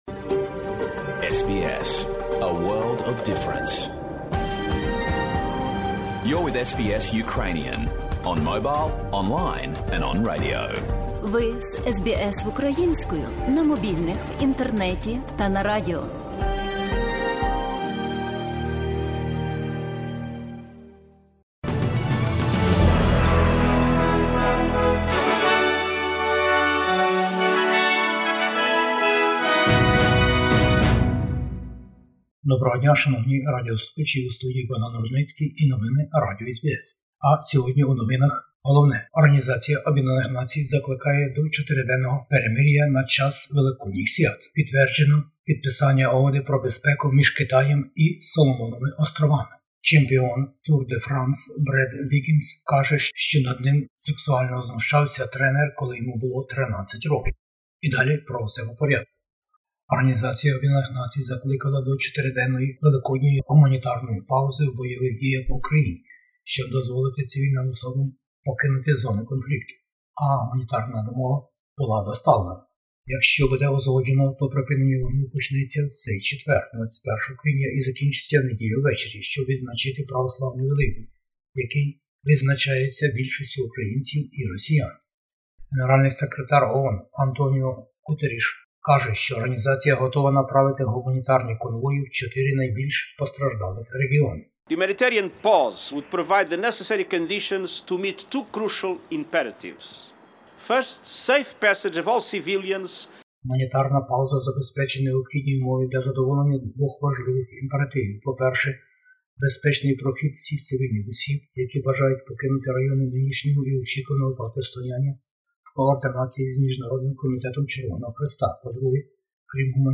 Бюлетень SBS новин українською мовою. ООН закликає Росію й Україну до перемиря на час наступних великодніх святкувань.